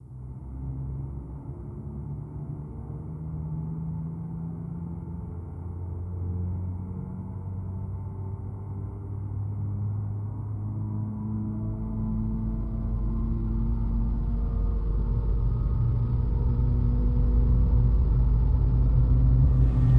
A320_cockpit_starter.wav